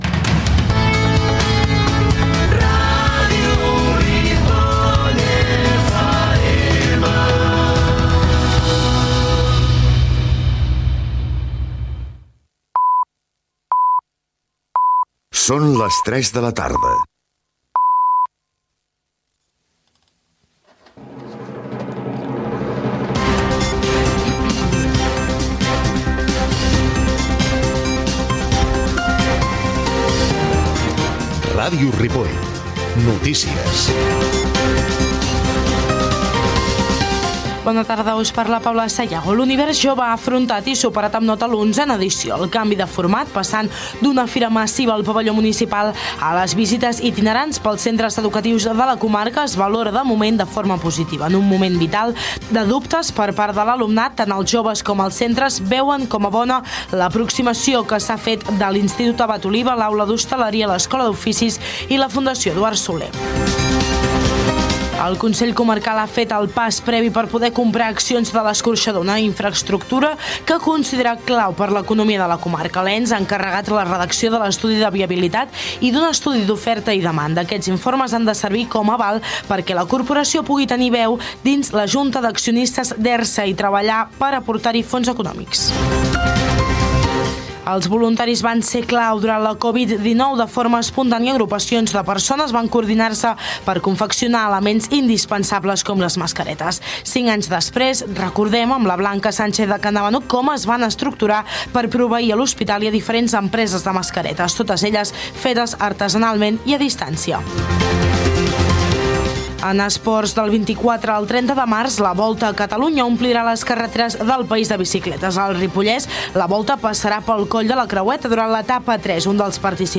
Indicatiu de la ràdio, hora, careta del programa, informació de la jornada "Univers jove", viabilitat de l'escorxador de la comarca, els voluntaris durant la Covid-19, pas de la Volta a Catalunya pel Ripollès, el temps. Indicatiu de la ràdio, tema musical
Informatiu